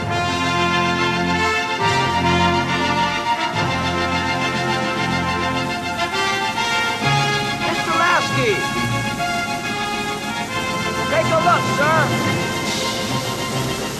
Here's a pitch shift example: a clip from The Final Countdown.
These are from the retail DVDs, both versions of which I own, not something I've manufactured.